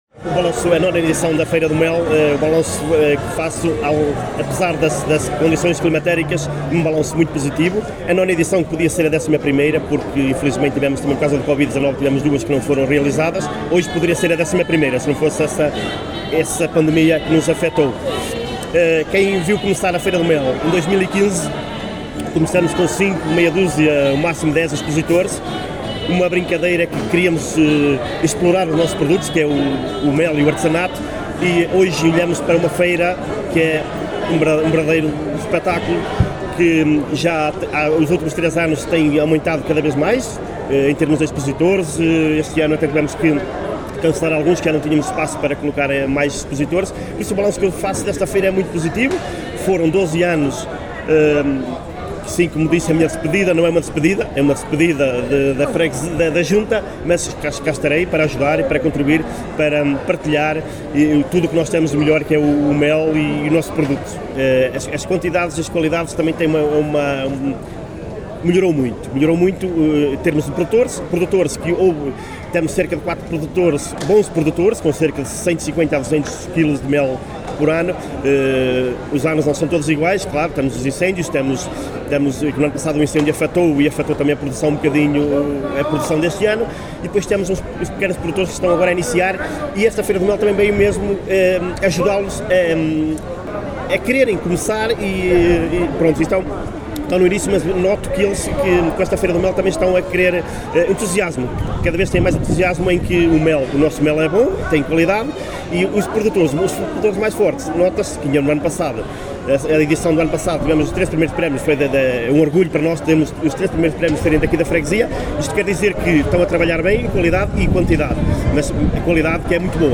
Jorge Cerdeira, Presidente da Junta de Freguesia de Pendilhe (Aldeia de Portugal), ao fazer um balanço muito positivo, afirmou que este evento ao longo dos anos tem vindo a crescer, “o mel é um excelente cartão de visita…”.